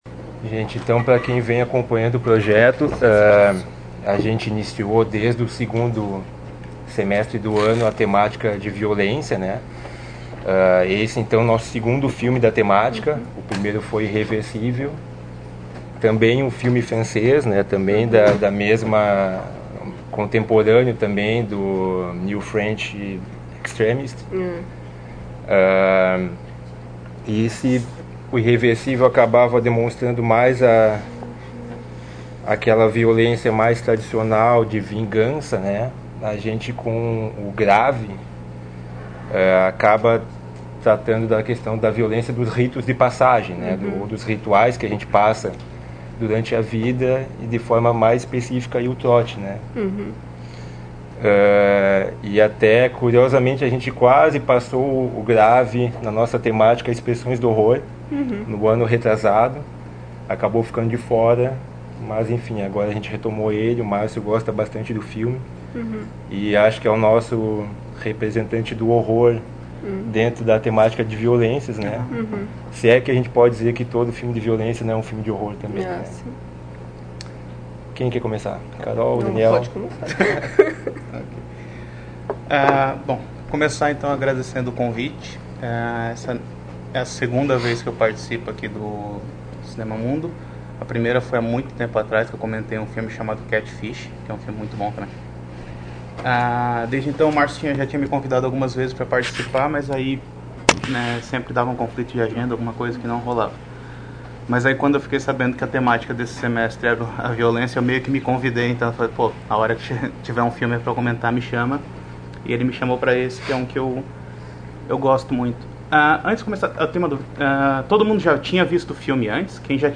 realizada em 29 de agosto de 2019 no Auditório Elke Hering da Biblioteca Central da UFSC